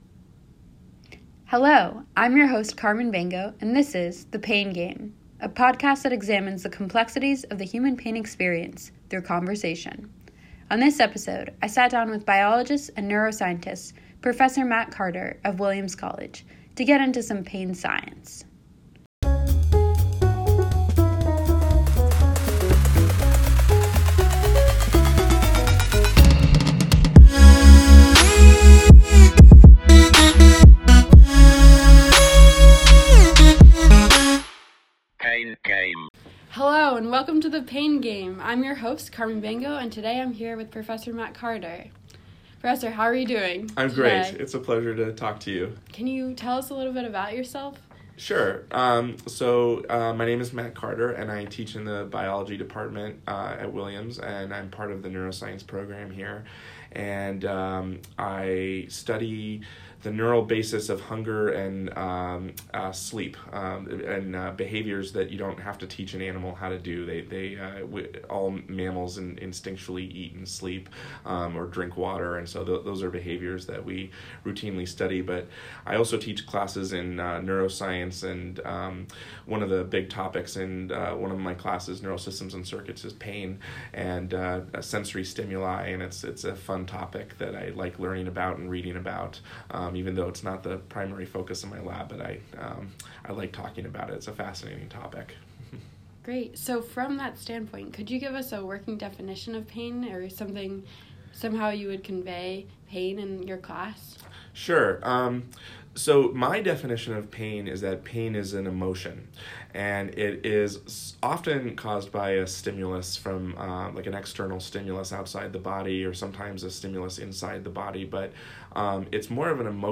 Episode 1: Pain Biology: An Interview